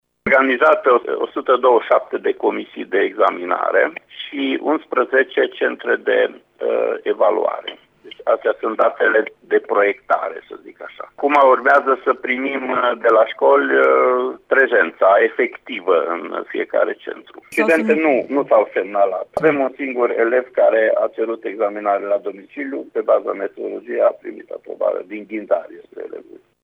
Un singur elev a cerut să susţină examenul acasă, a declarat, pentru RTM, inspectorul școlar general al județului Mureș, Ştefan Someşan: